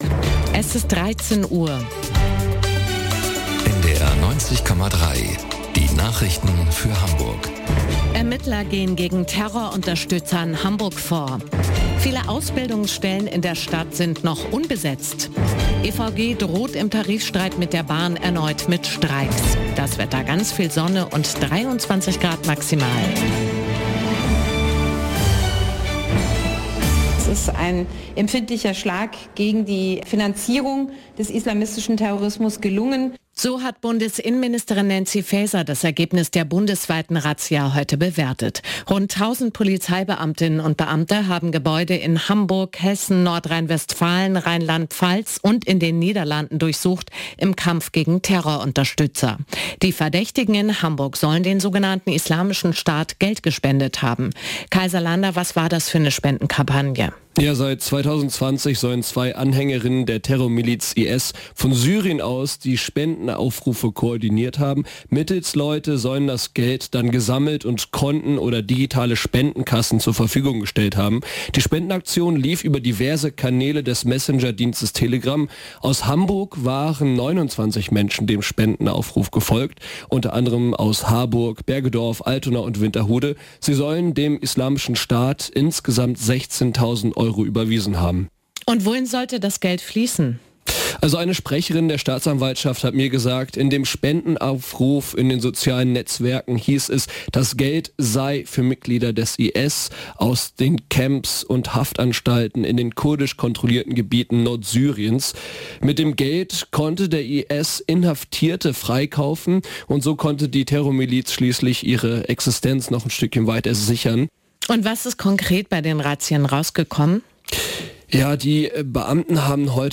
Nachrichten - 27.08.2023